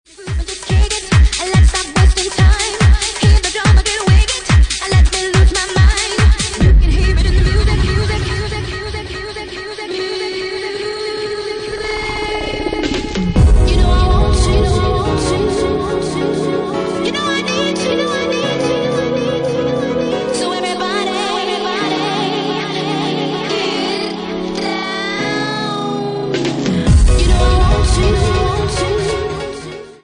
Bassline House at 143 bpm